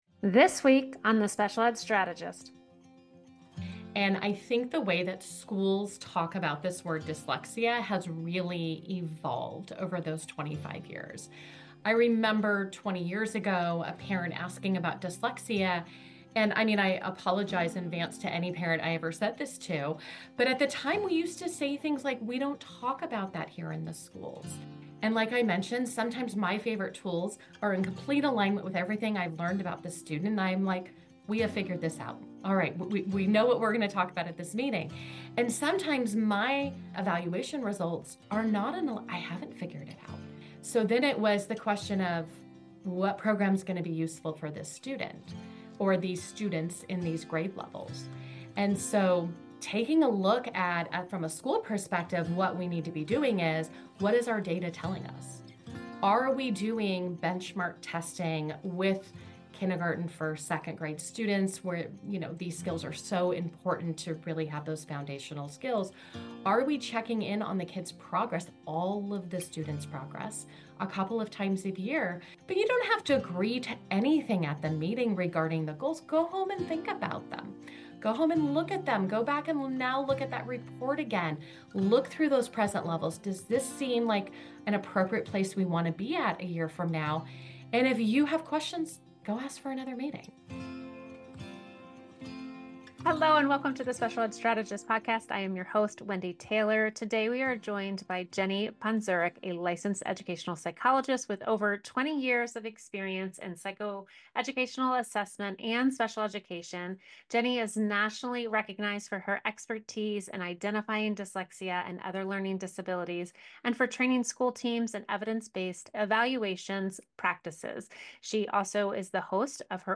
You’ll hear about the importance of asking the right questions, how to understand the difference between medical and educational definitions of dyslexia, and what it really means to collaborate with your IEP team as a data-informed parent. Whether your child is just starting to struggle with reading or already has an IEP in place, this conversation is filled with guidance, clarity, and actionable next steps.